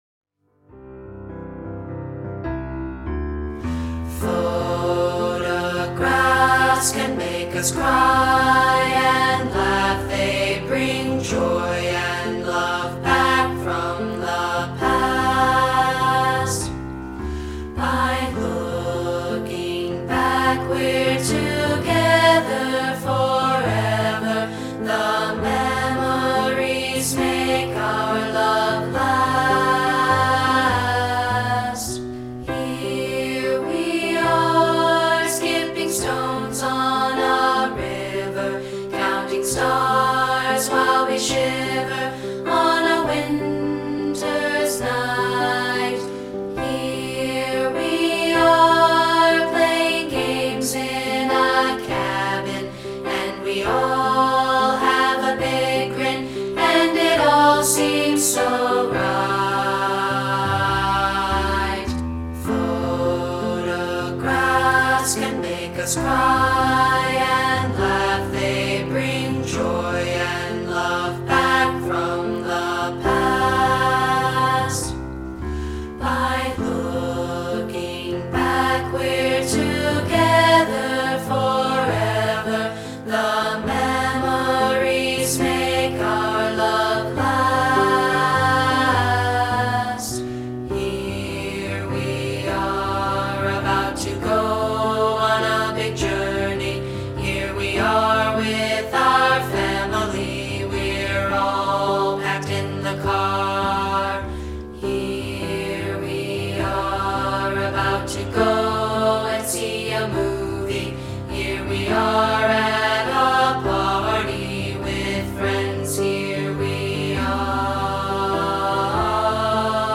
including a rehearsal track of part 3, isolated.